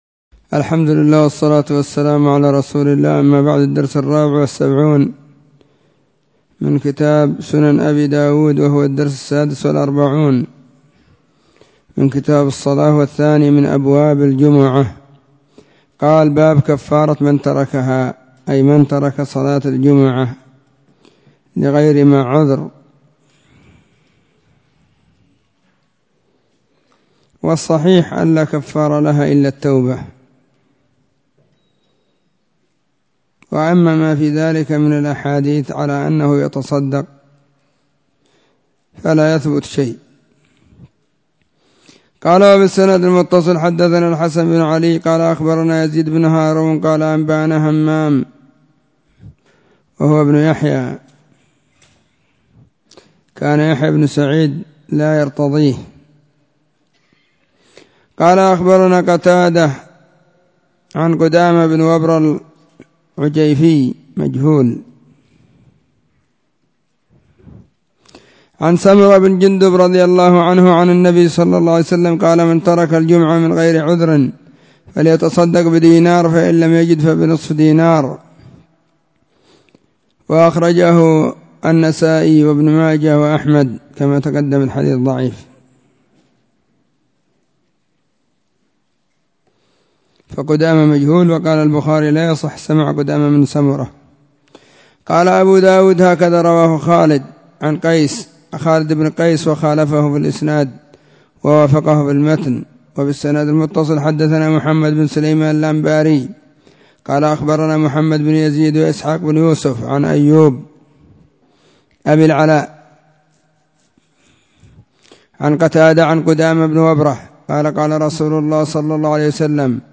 🕐 [بعد صلاة العصر في كل يوم الجمعة والسبت]
📢 مسجد الصحابة بالغيضة, المهرة، اليمن حرسها الله.